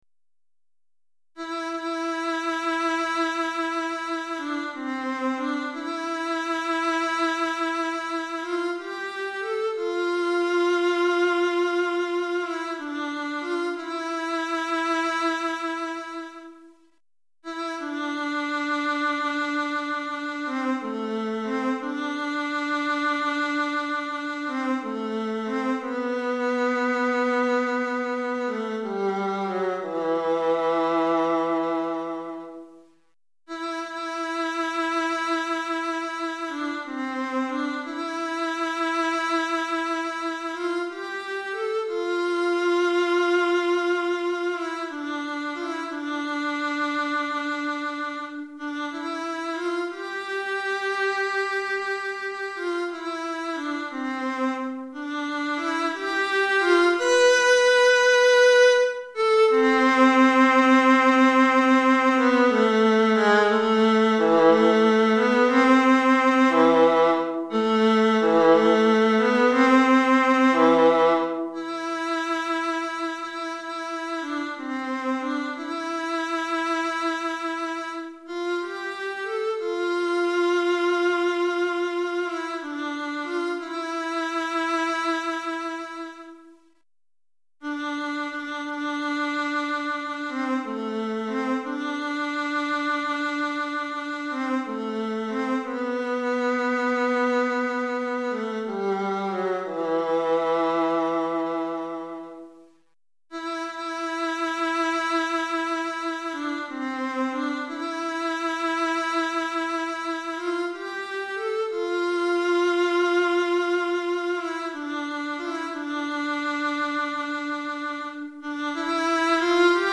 Répertoire pour Alto - Alto Solo